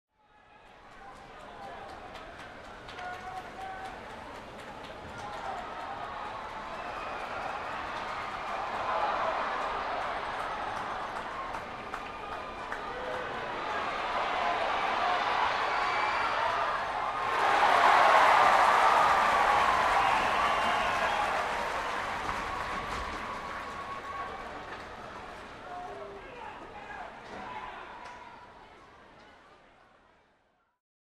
Звуки спорта
Шум трибун во время матча, крики после забитого гола